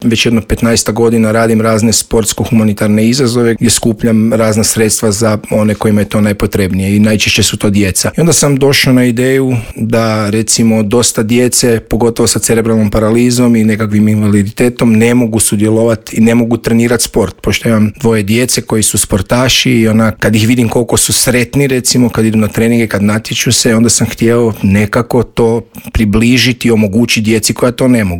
Gost u Intervjuu Media servisa